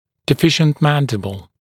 [dɪ’fɪʃnt ‘mændɪbl][ди’фишнт ‘мэндибл]недоразвитие нижней челюсти; нижняя челюсть недостаточного размера